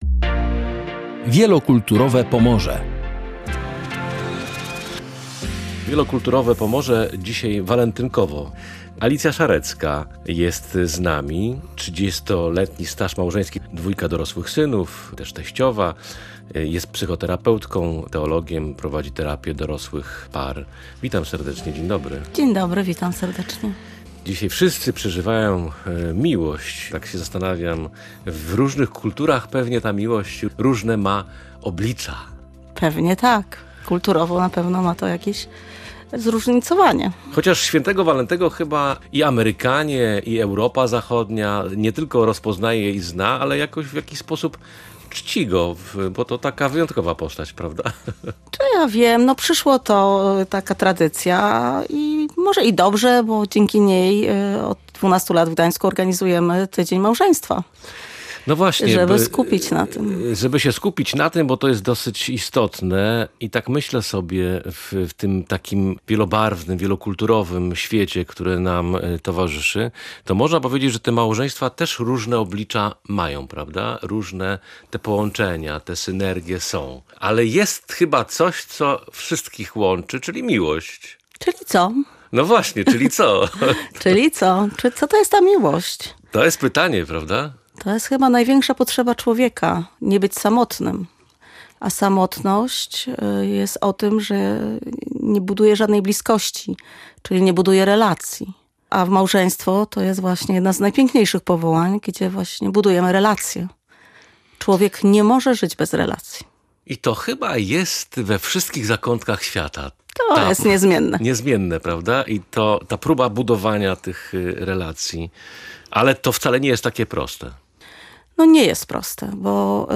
Gościem audycji „Wielokulturowe Pomorze”